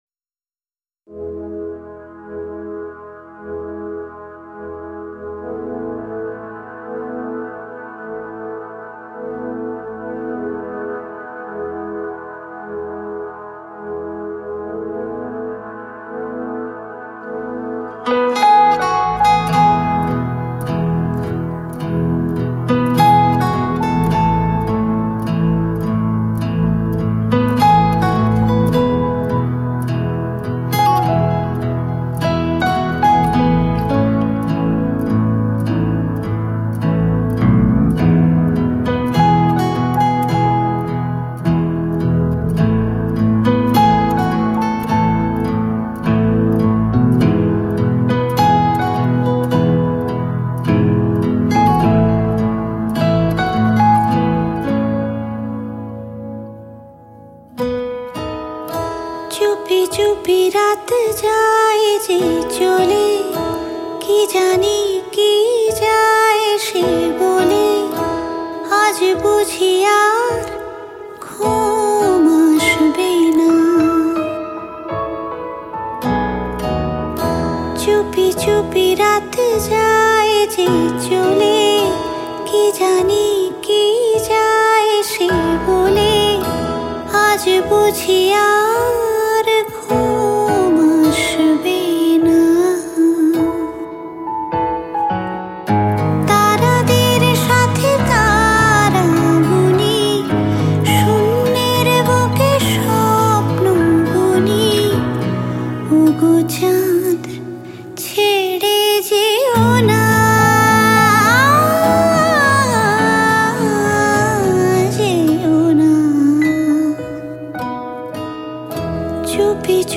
Songs Mp3 Bengali